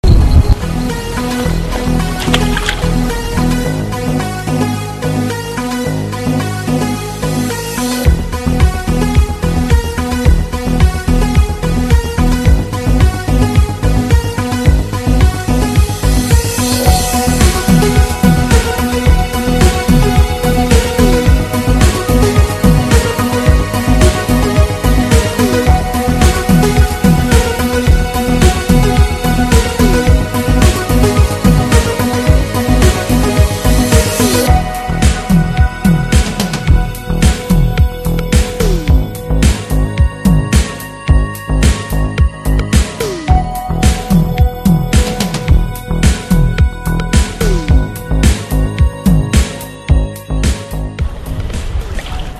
Пожалуйста подскажите название электронной мелодии с 31:34.